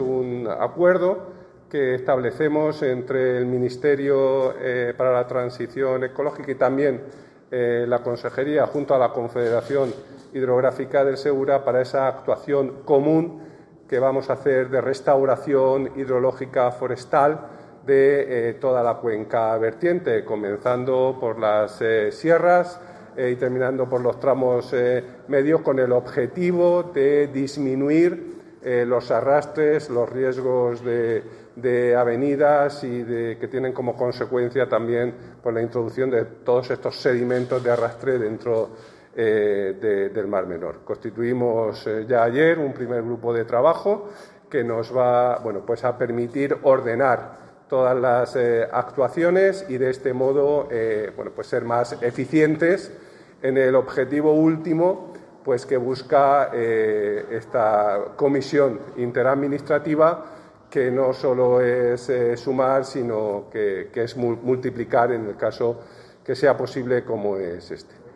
• Audio del Consejero de Medio Ambiente, Universidades, Investigación y Mar Menor, Juan María Vázquez